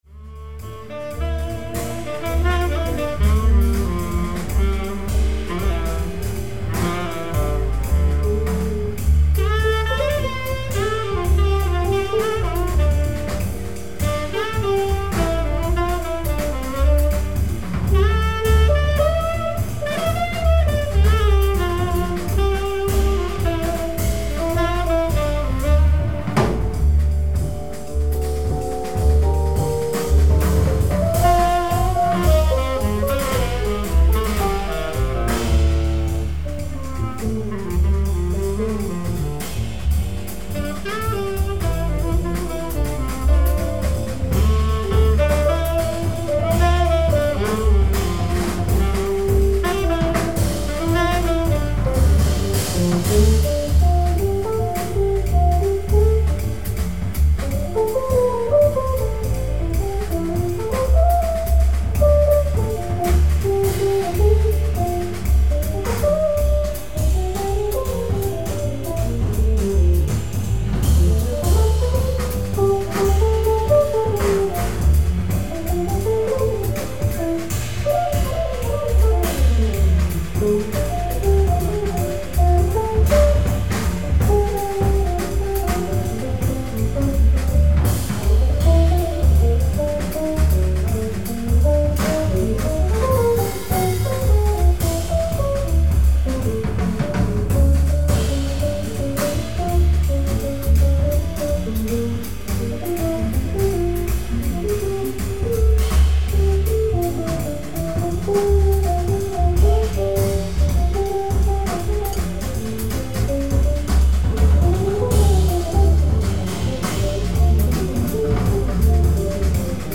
ライブ・アット・サンタ・クルーズ、カリフォルニア 09/14/2000
※試聴用に実際より音質を落としています。